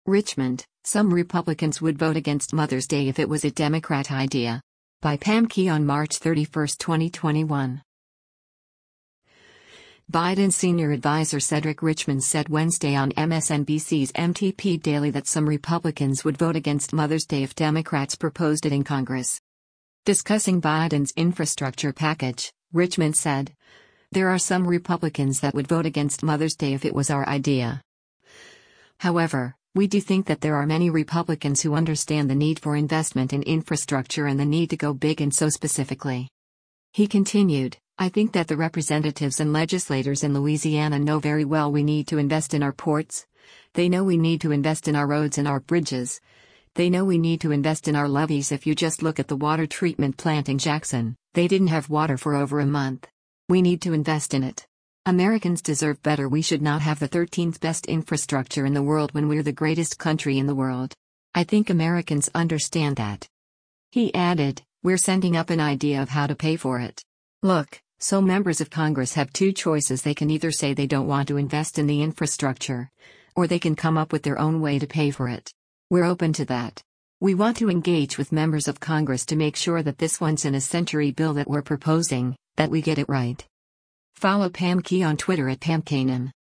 Biden senior adviser Cedric Richmond said Wednesday on MSNBC’s “MTP Daily” that some Republicans “would vote against Mother’s Day” if Democrats proposed it in Congress.